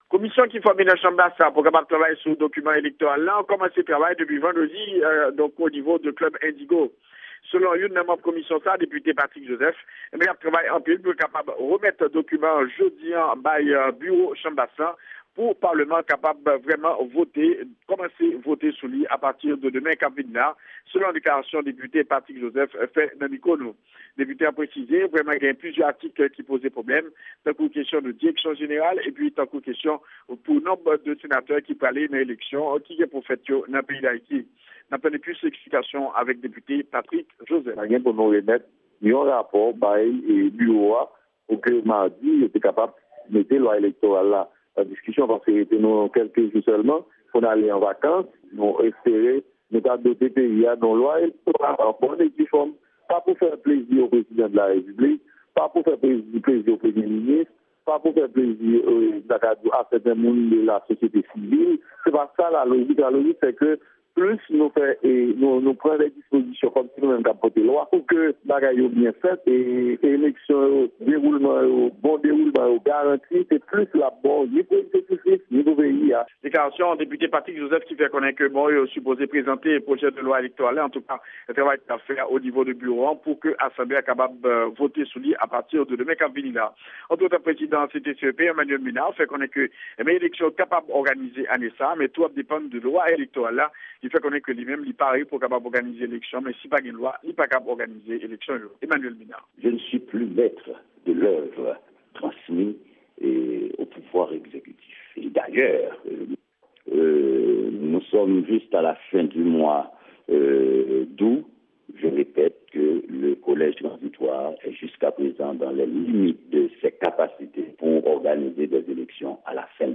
Repòtaj sou Travay Komisyon Chanm Bas la sou Pwojè-Lwa Elektoral la